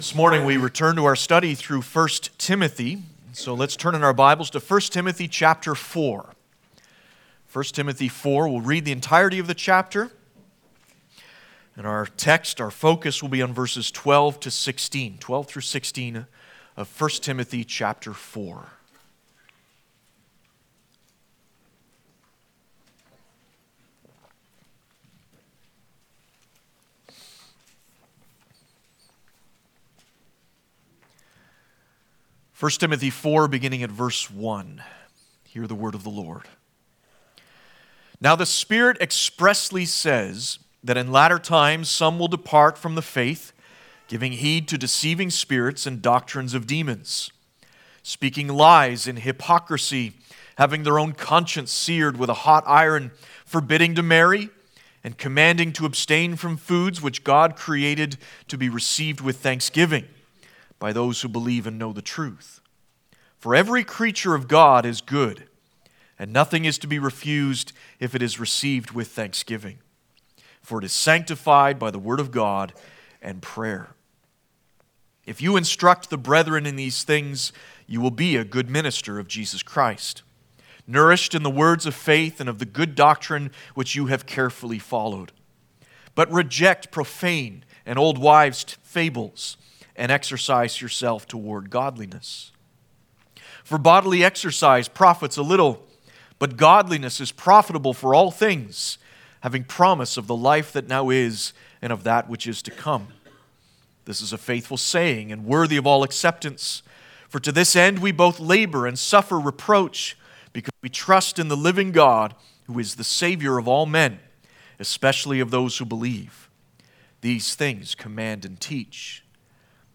Passage: 1 Timothy 4:12-16 Service Type: Sunday Morning